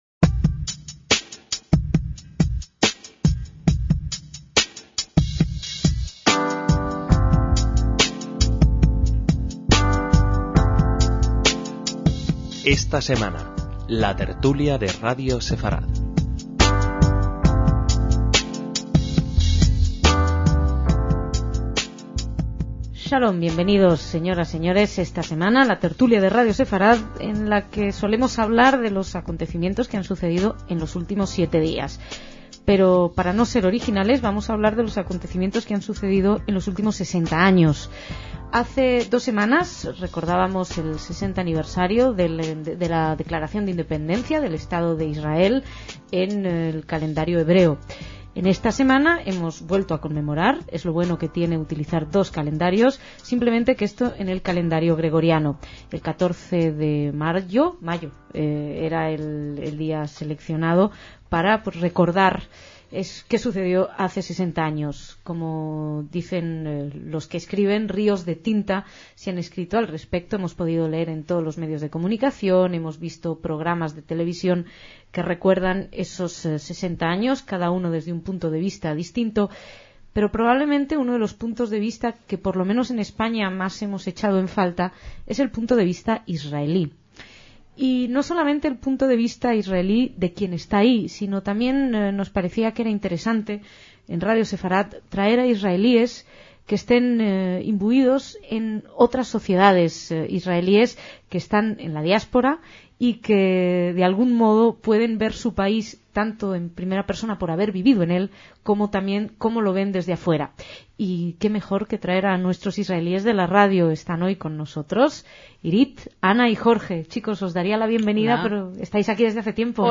Cuando se cumplieron 60 años del nacimiento del Estado de Israel, dedicamos un programa especial con invitados israelíes del propio personal